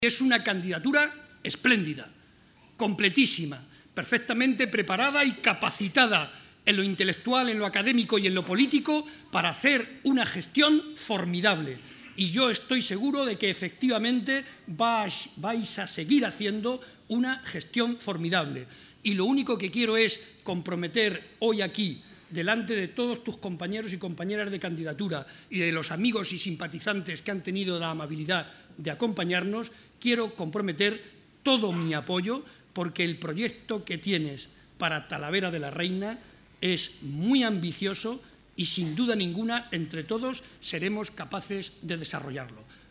Barreda junto a Rivas en el acto celebrado en Talavera.
Barreda hizo estas manifestaciones en Talavera de la Reina, durante la presentación de la candidatura a la Alcaldía que encabeza José Francisco Rivas y que ha tenido lugar en el Instituto “Juan Antonio Castro”, donde el actual alcalde se ha formado.